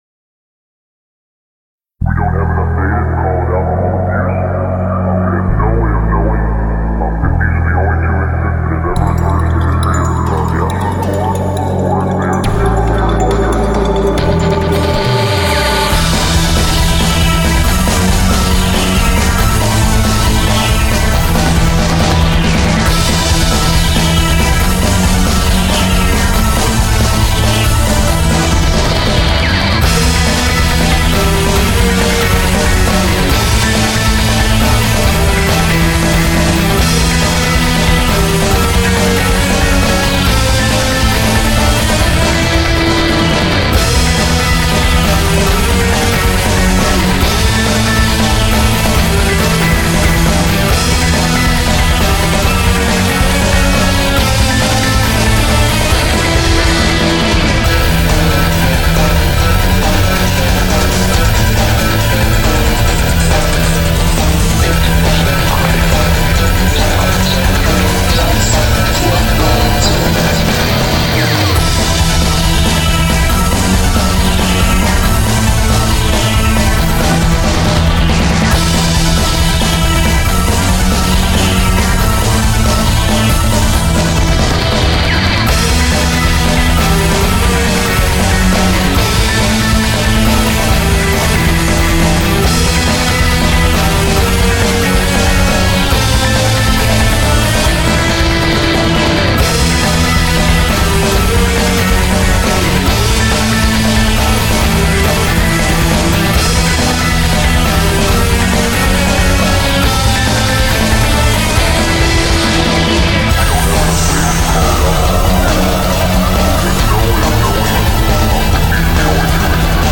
今回はボス戦の曲なので派手にしてみました。
イントロ、間奏、エンディングの怪しいボコーダーボイスはサンプリングCDからです。